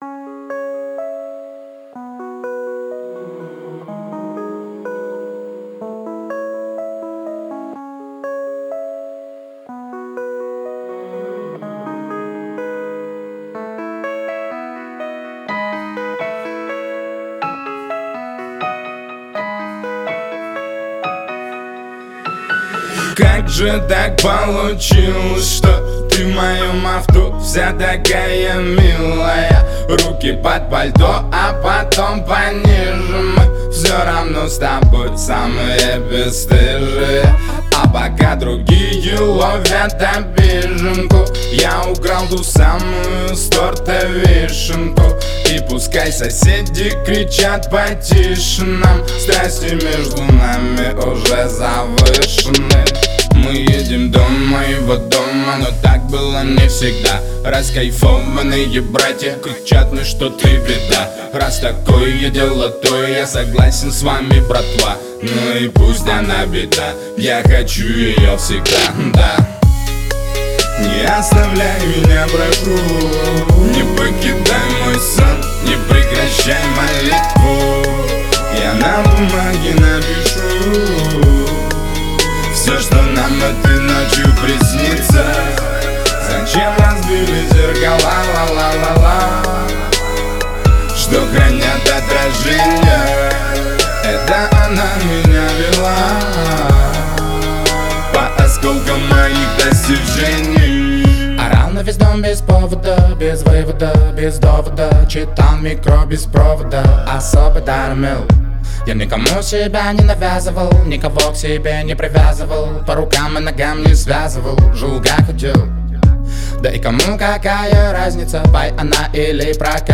Русские песни